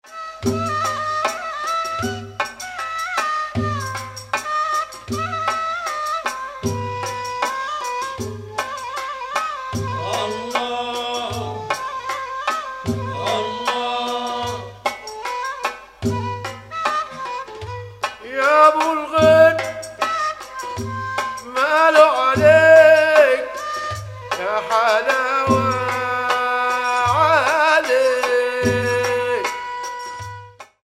Zar music is raw and unpolished.